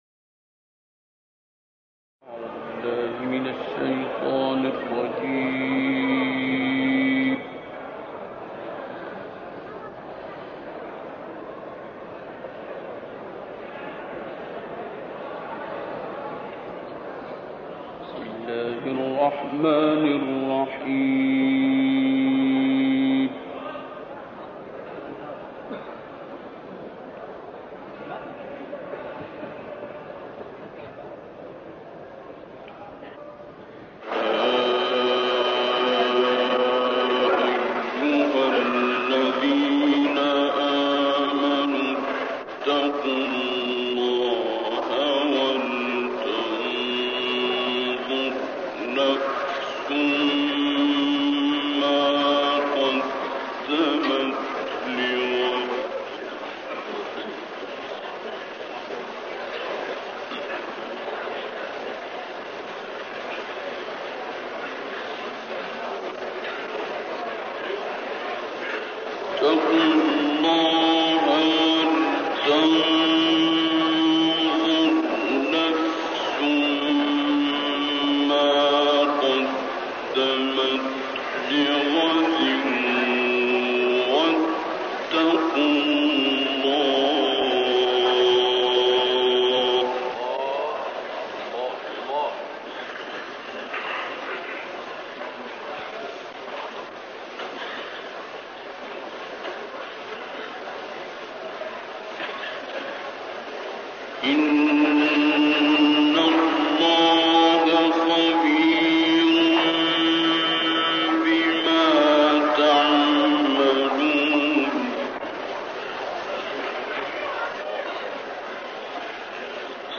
برترین تلاوت عبد الباسط سوره حشر-تکویر-فجر
برترین-تلاوت-عبد-الباسط-سوره-حشر-تکویر-فجر.mp3